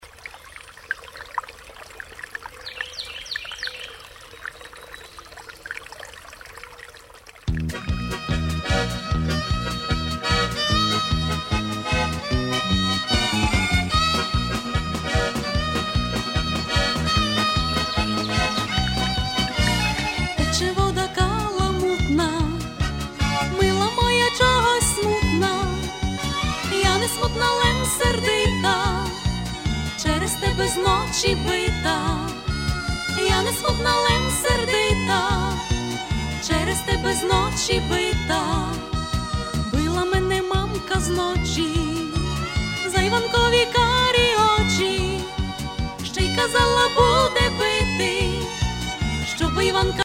Collection of Folk Songs.